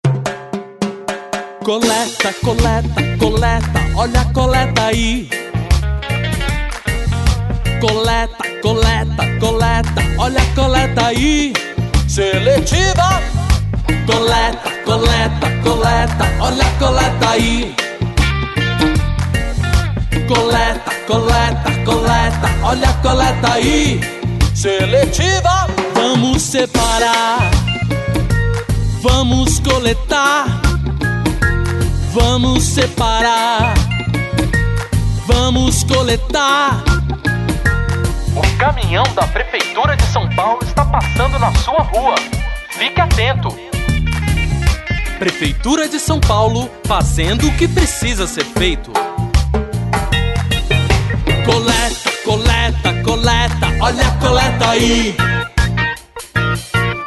aqui o jingle da coleta seletiva.